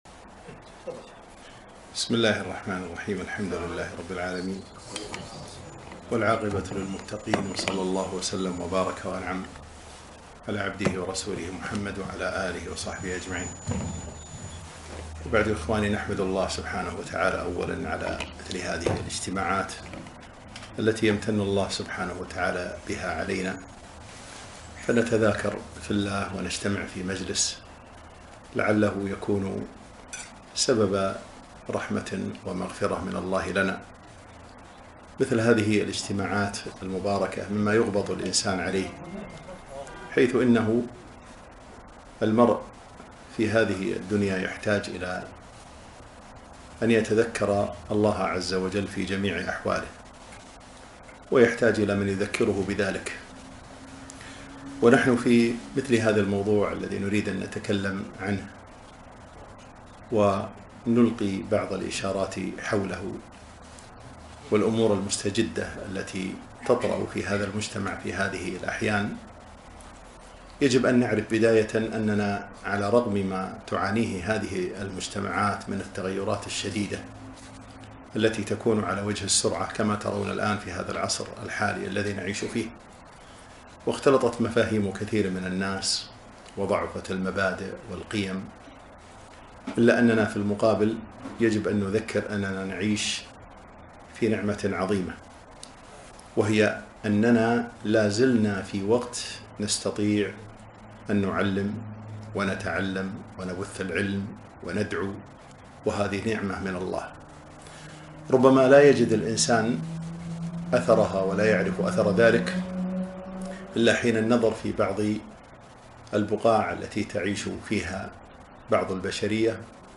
محاضرة - ضرورة إصلاح المجتمع